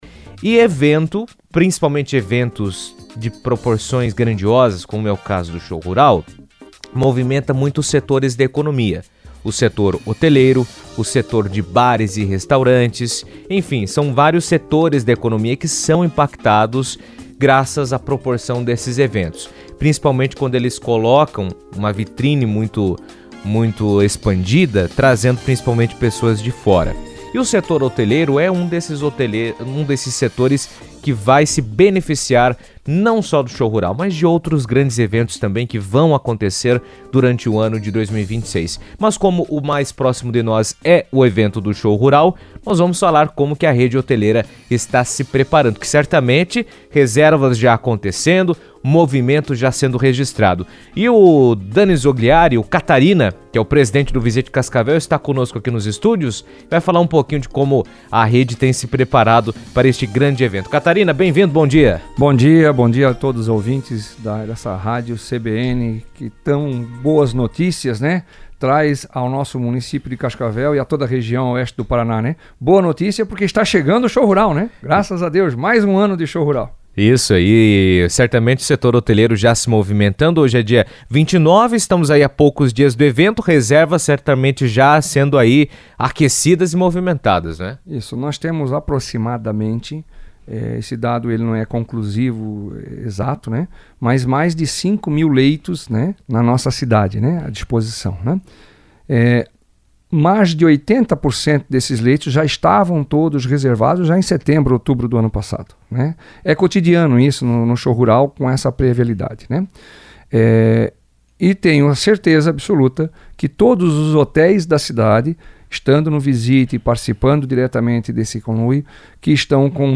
Editoriais
Entrevista